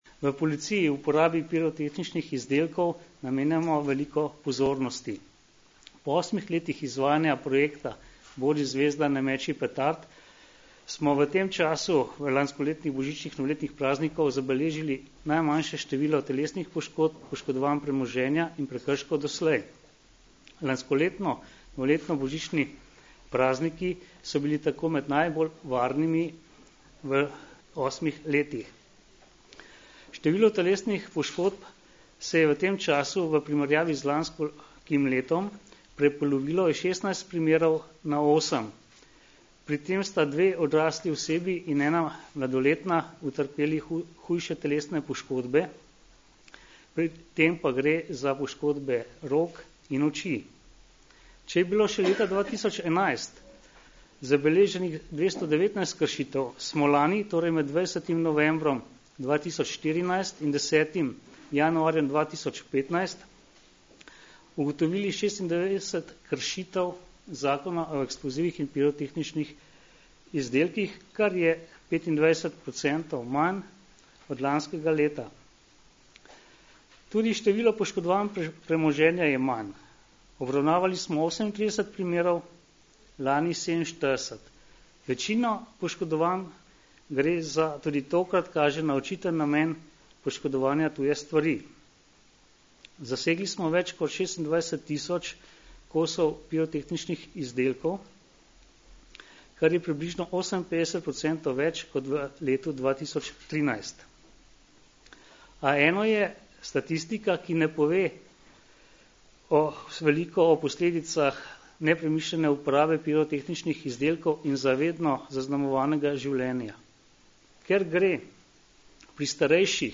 Zvočni posnetek izjave
»Če je bilo še leta 2011 zabeleženih 219 kršitev, smo lani, torej med 20. novembrom 2014 in 10. januarjem 2015, ugotovili 96 kršitev določb Zakona o eksplozivih in pirotehničnih izdelkih ali 25 % manj kršitev kot v letu pred tem,« je na današnji novinarski konferenci povedal